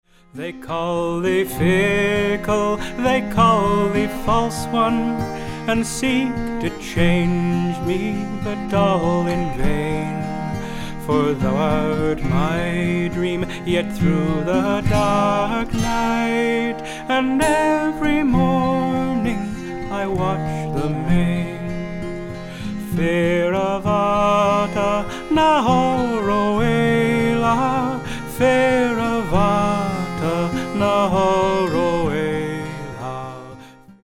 - beautiful Scottish ballad